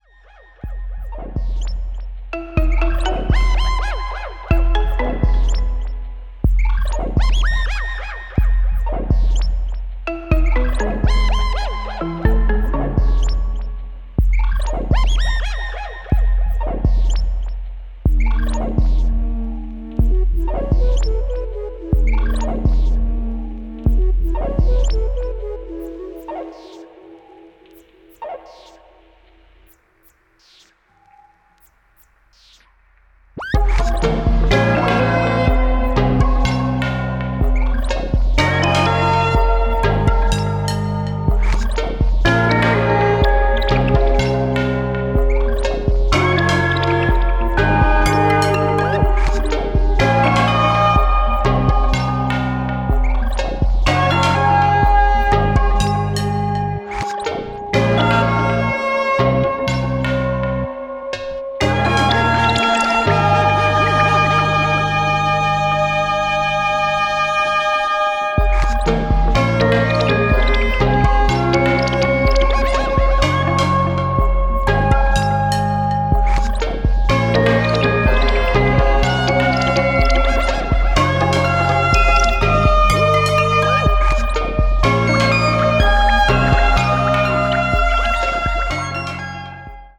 Electronix House